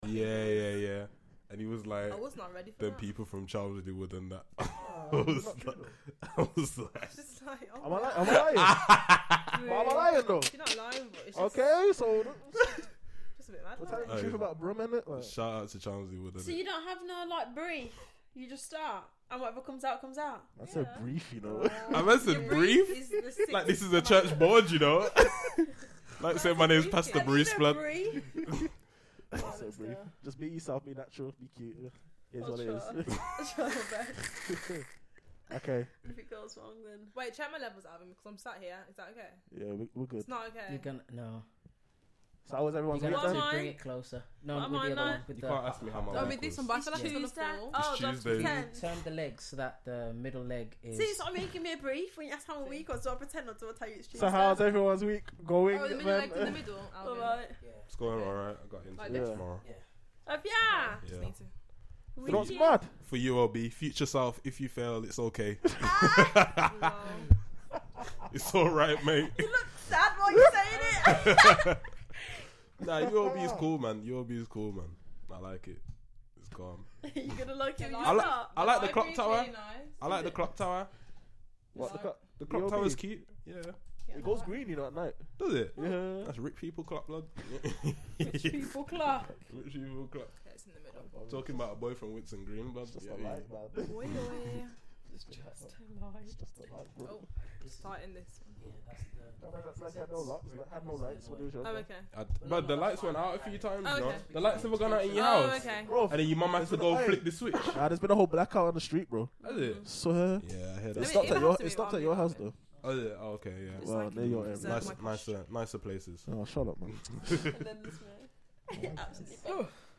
A youthful but in depth discussion about the importance of honouring your parents, killing and committing adulterous acts and the damaging effects it places on the soul and mind.